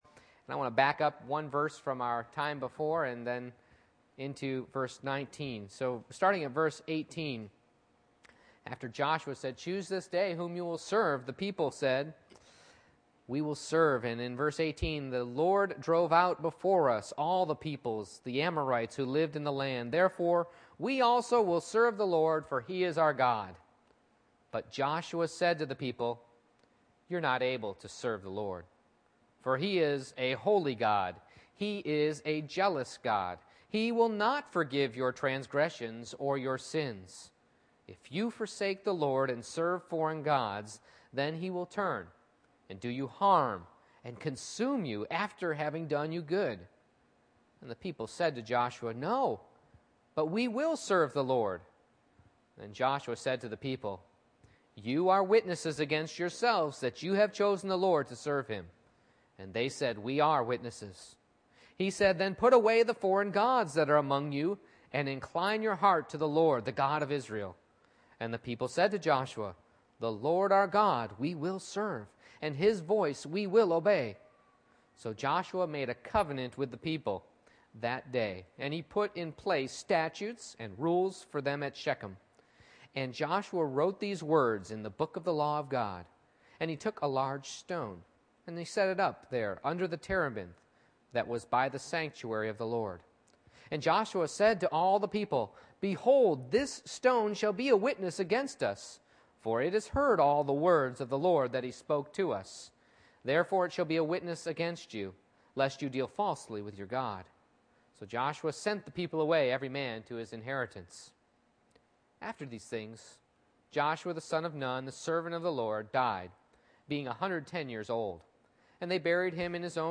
Joshua 24:18-33 Service Type: Morning Worship I. Can I Serve Him?